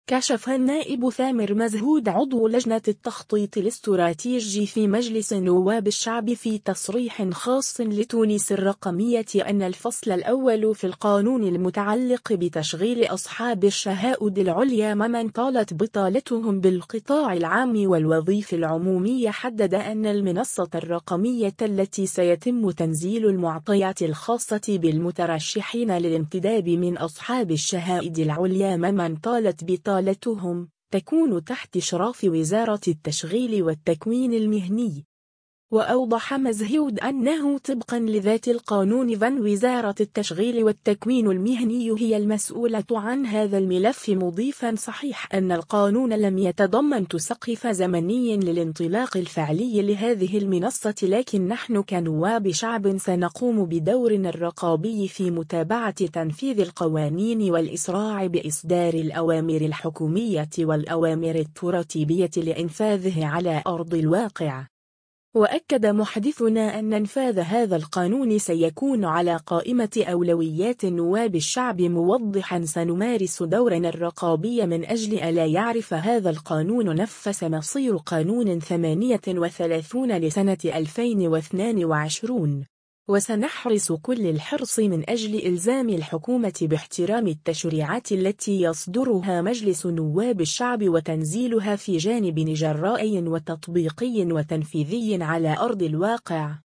كشف النائب ثامر مزهود عضو لجنة التخطيط الاستراتيجي في مجلس نواب الشعب في تصريح خاص لـ”تونس الرقمية” أن الفصل الأول في القانون المتعلق بتشغيل أصحاب الشهائد العليا ممن طالت بطالتهم بالقطاع العام والوظيف العمومية حدد أن المنصة الرقمية التي سيتم تنزيل المعطيات الخاصة بالمترشحين للانتداب من أصحاب الشهائد العليا ممن طالت بطالتهم، تكون تحت اشراف وزارة التشغيل والتكوين المهني.